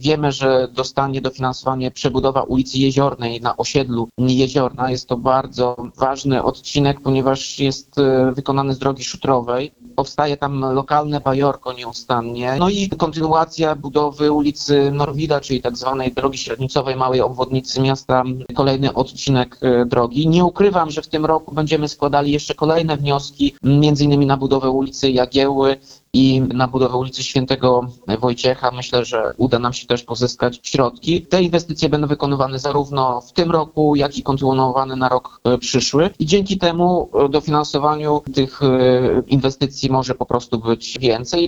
– To cenne wsparcie – mówi Tomasz Andrukiewicz, prezydent Ełku.